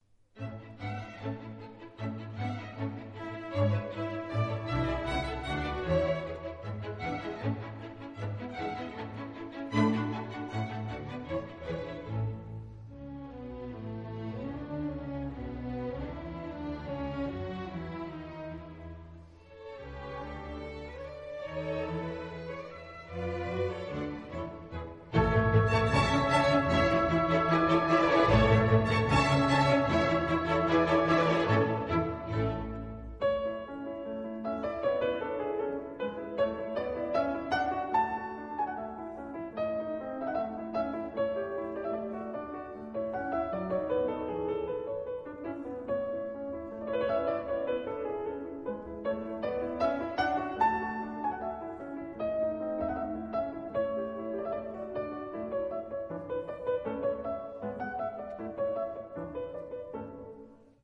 Piano Concerto
... lively phrasing and warmth of touch